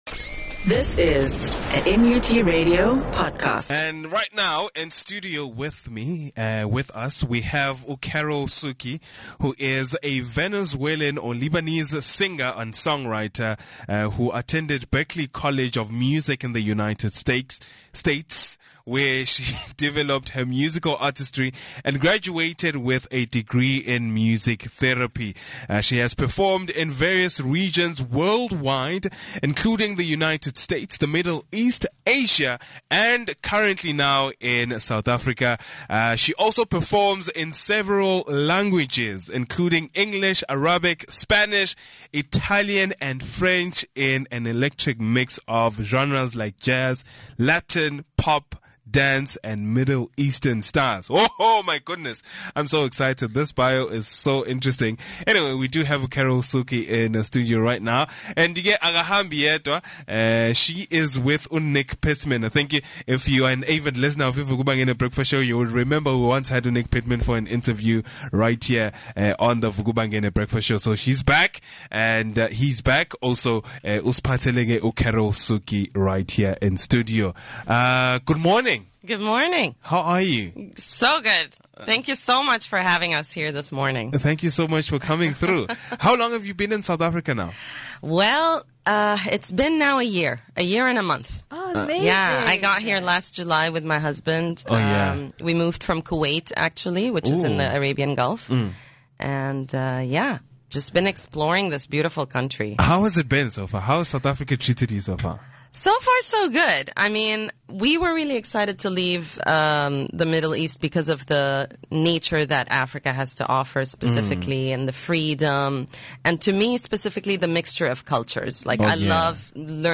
interview
live unplugged performance